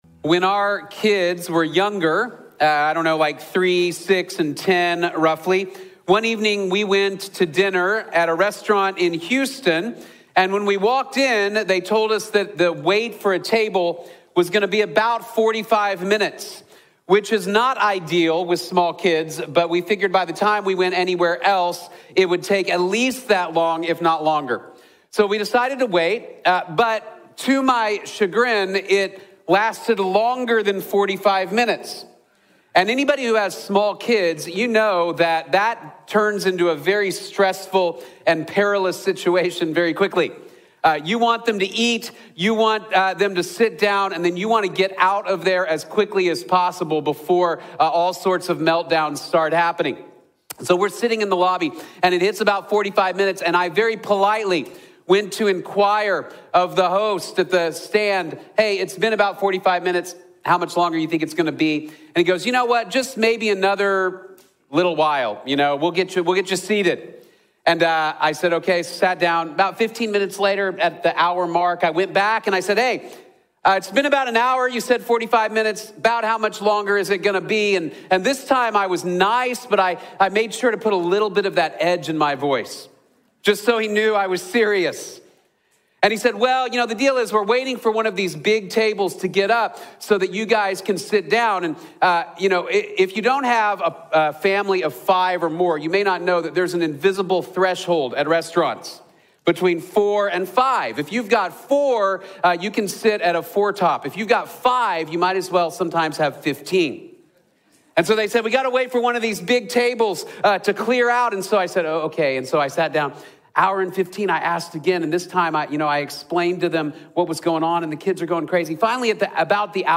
While You’re Waiting | Sermon | Grace Bible Church